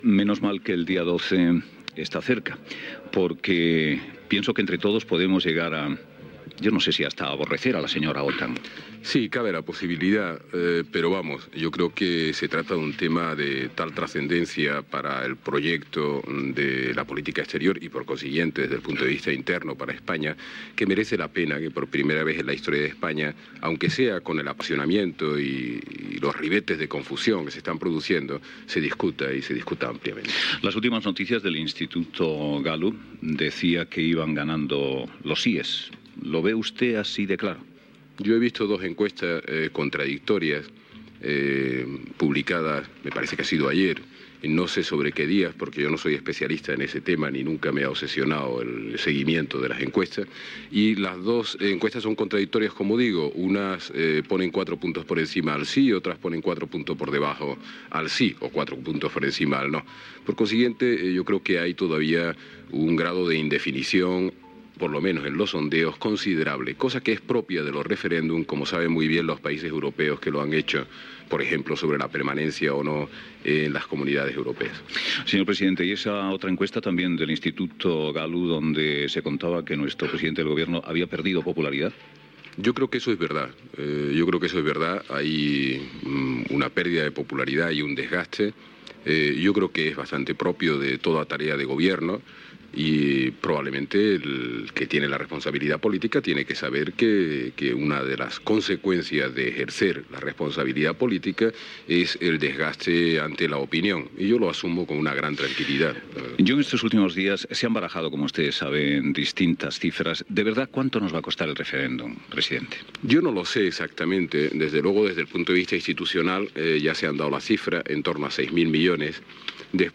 Fragment d'una entrevista al president del Govern espanyol Felipe González pocs dies abans del referèndum de l'OTAN
Info-entreteniment